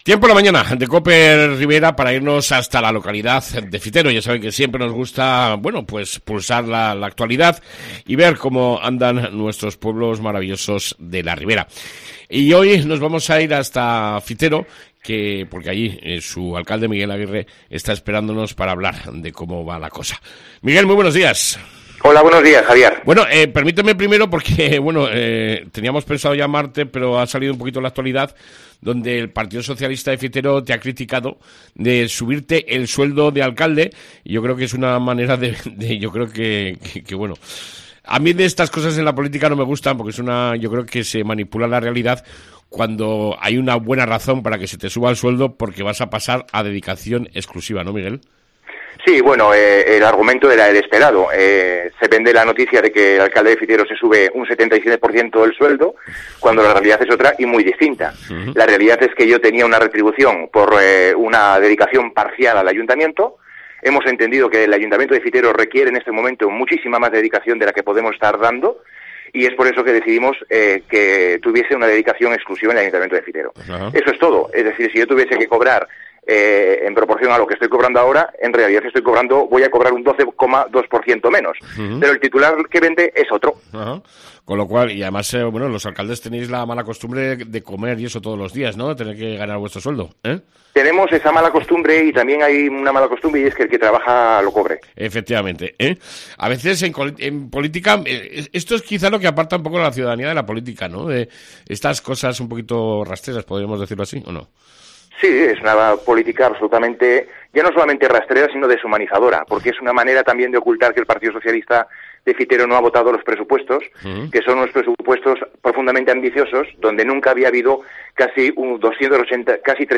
AUDIO: Entrevista con el Alcalde de Fitero Miguel Aguirre